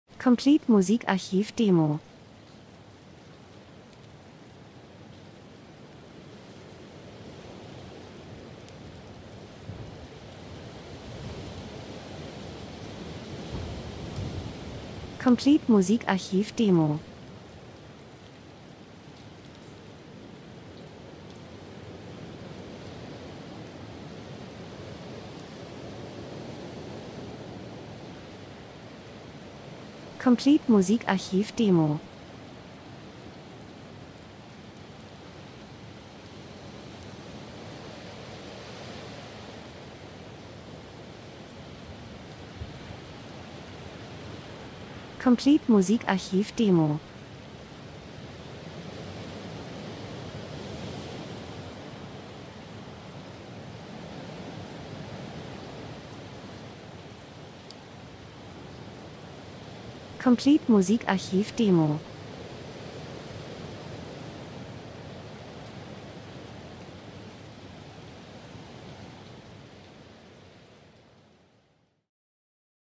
Herbst -Geräusche Soundeffekt Wind, offenes Feld, Regen 01:12